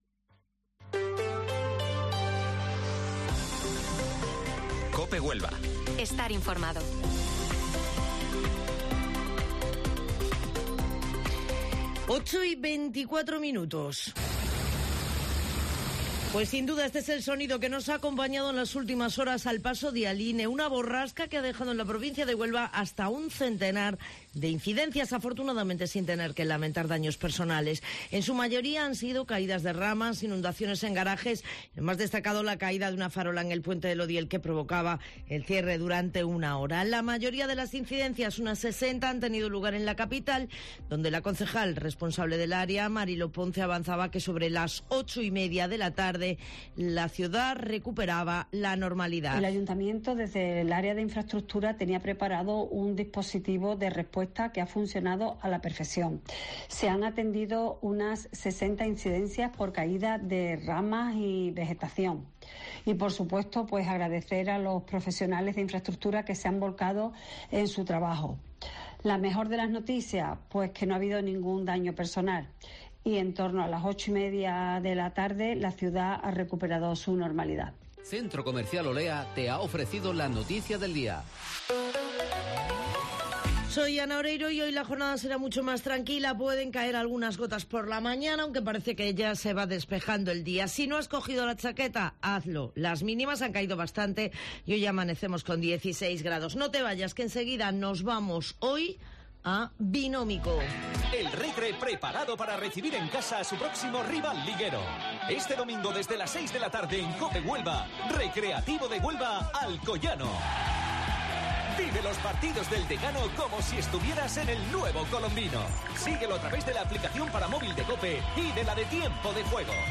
Informativo Matinal Herrera en COPE 20 de octubre